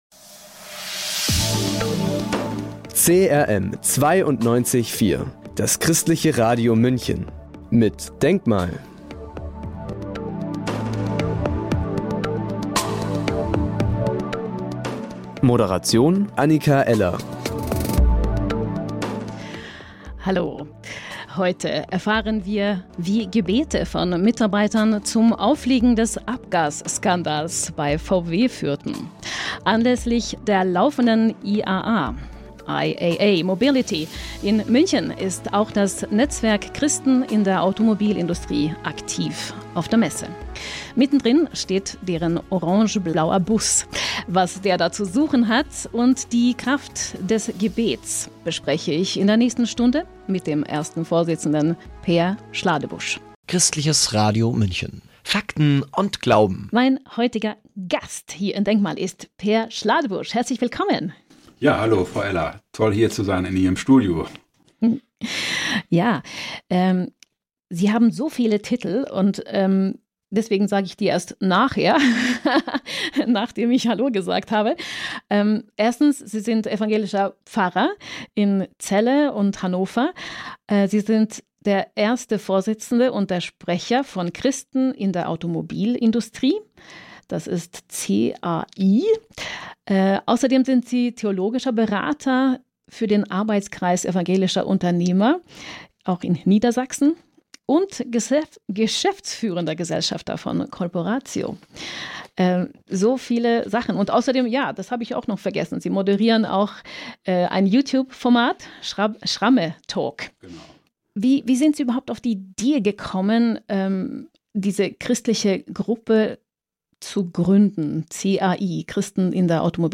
Produktion, Interviews und Moderation